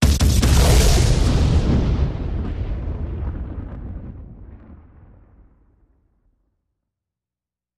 Explosions; Multiple 03